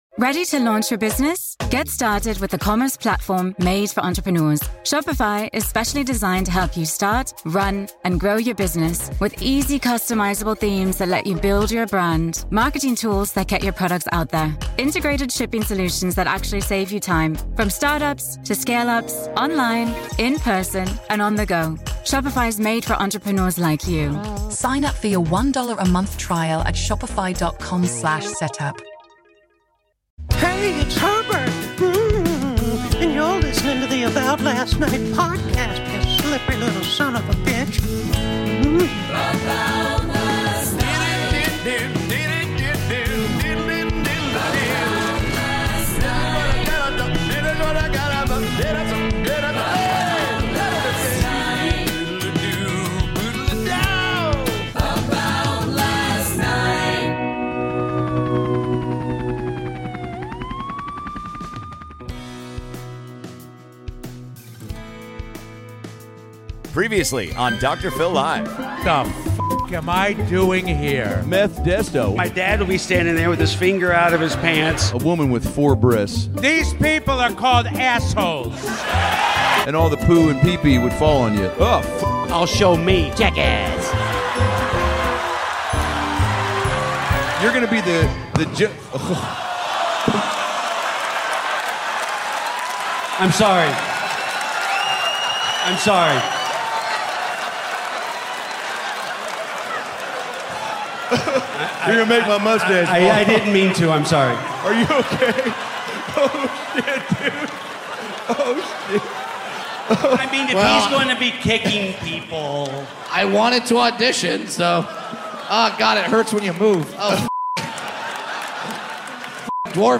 Dr. Phil LIVE! is coming to you from San Fransisco, California with a show so big we had to release it in two parts!